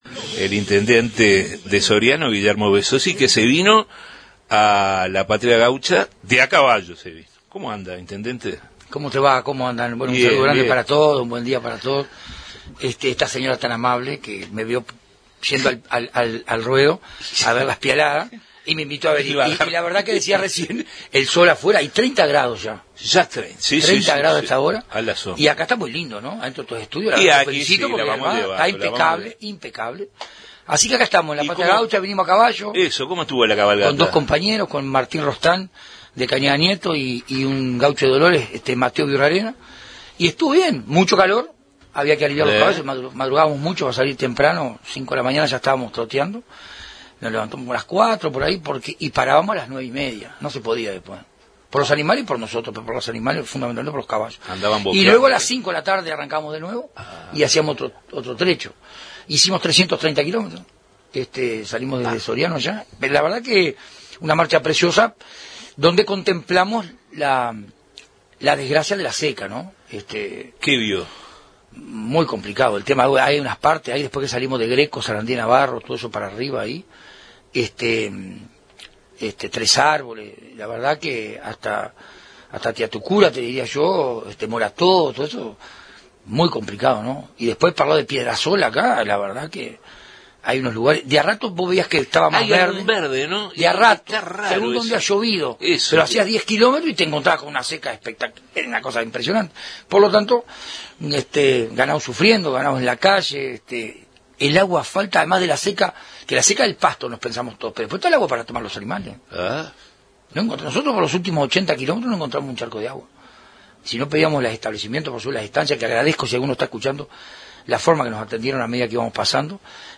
En Justos y pecadores entrevistamos al intendente de Soriano, Guillermo Besozzi, al que recibimos en el estudio móvil de los Medios Públicos, desde la Fiesta de la Patria Gaucha, en esta emisión especial de Radio Uruguay